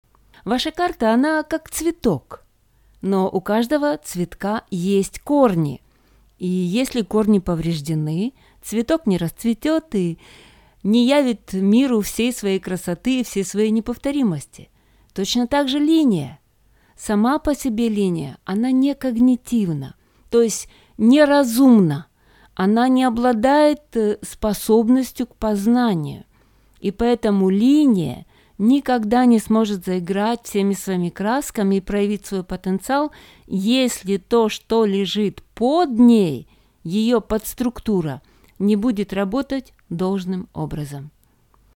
Образцы моего голоса: